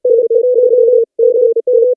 40hz.wav